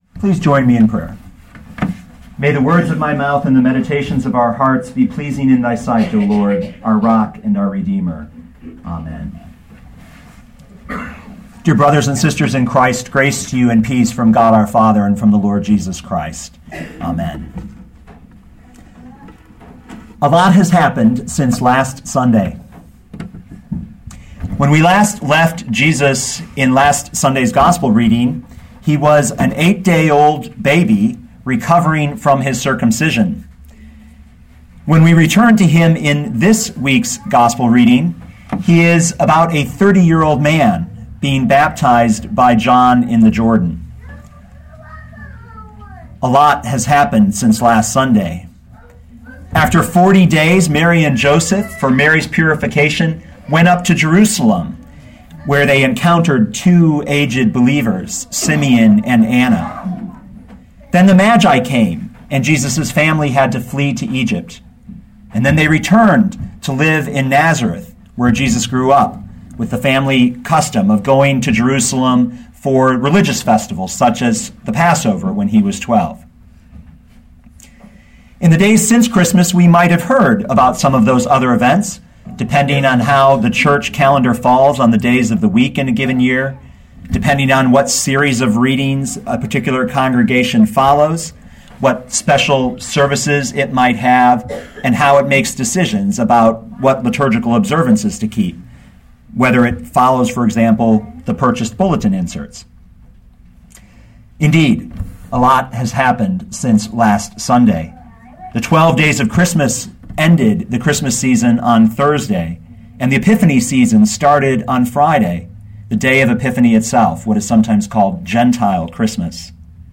2012 Mark 1:9-11 Listen to the sermon with the player below, or, download the audio.